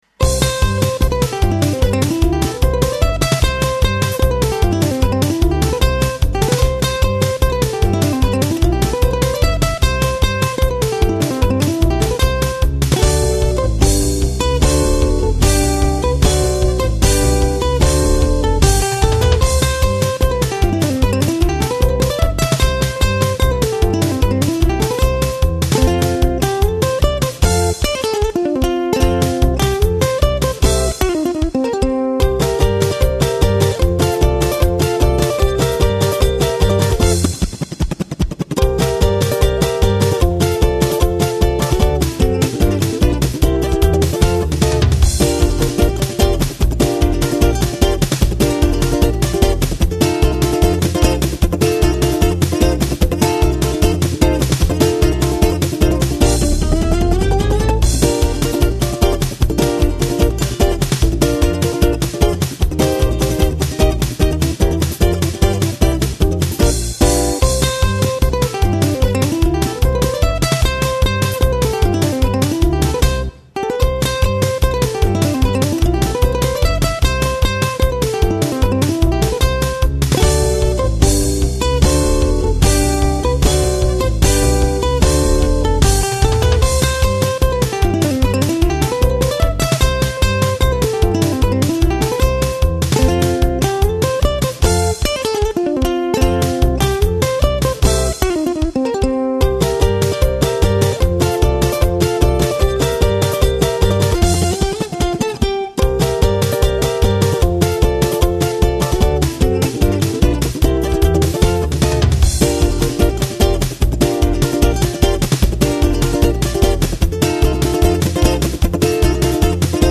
Kanadischer Blues-Ukulist und Songwriter: “Ich liebe meine RISA-Electric-Soprano in erster Linie und am meisten wegen ihres Sounds.
Electric-Soprano